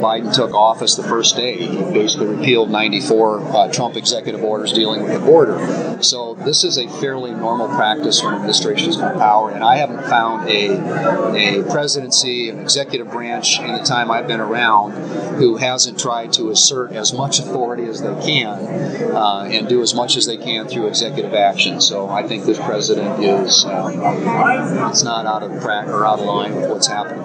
WATERTOWN, S.D.(KXLG)- U.S. Senator John Thune visited Watertown Thursday to address the local Rotary Club during their meeting held at the Elks Lodge.